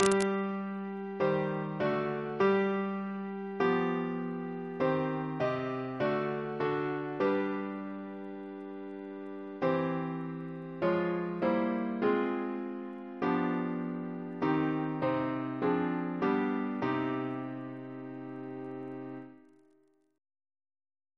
Double chant in C minor Composer